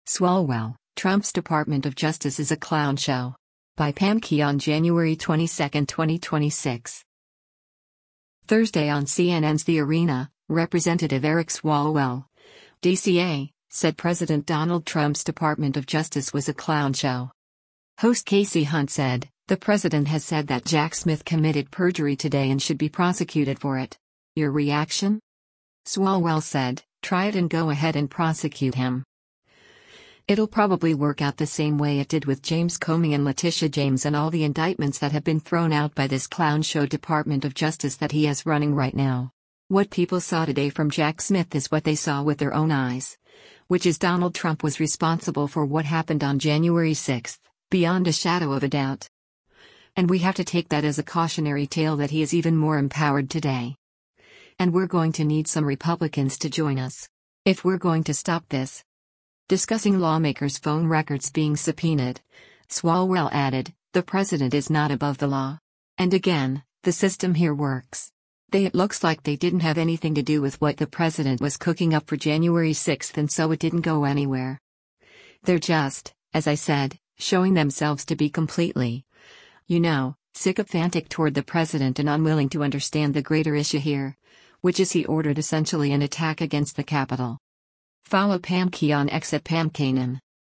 Thursday on CNN’s “The Arena,” Rep. Eric Swalwell (D-CA) said President Donald Trump’s Department of Justice was a “clown show.”
Host Kasie Hunt said, “The president has said that Jack Smith committed perjury today and should be prosecuted for it. Your reaction?”